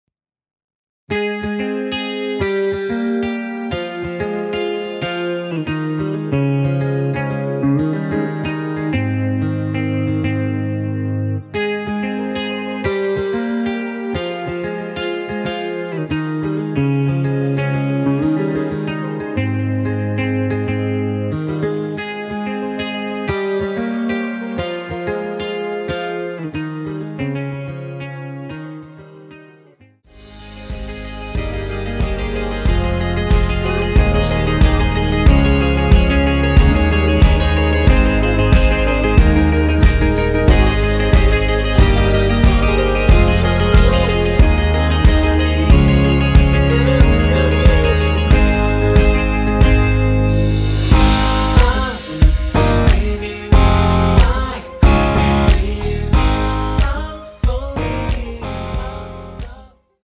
원키에서(-1)내린 (2절 삭제)하고 진행 되는 멜로디와 코러스 포함된 MR입니다.(미리듣기 확인)
Ab
앞부분30초, 뒷부분30초씩 편집해서 올려 드리고 있습니다.